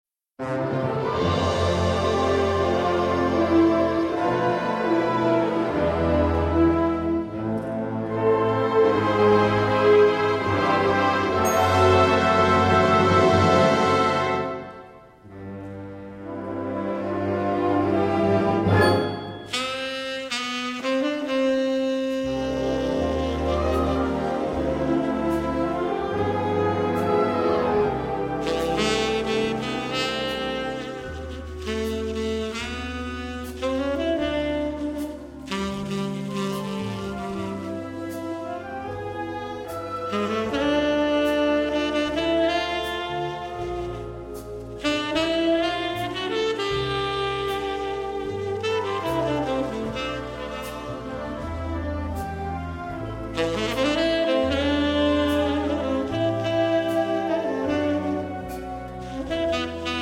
guitar
clarinet soloist